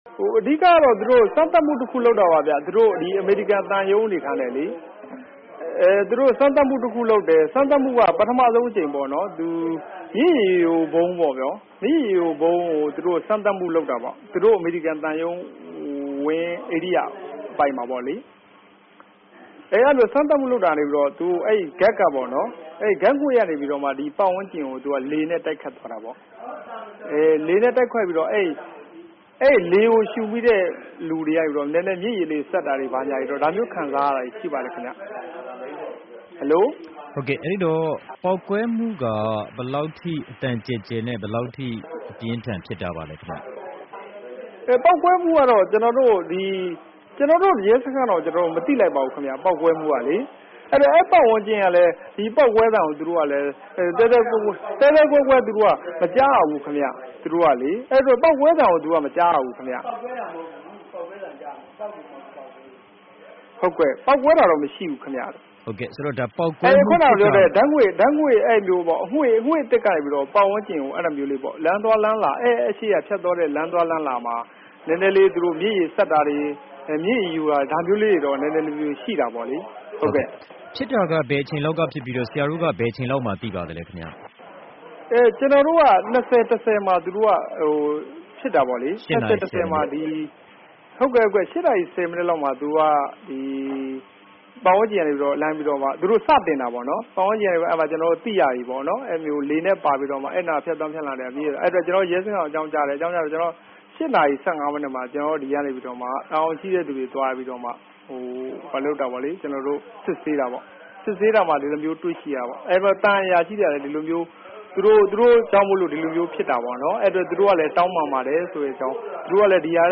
ရန်ကုန်မြို့ အမေရိကန်သံရုံးဖြစ်ရပ်နှင့်ပတ်သက်၍ ဆက်သွယ်မေးမြန်းချက် အပြည့်အစုံ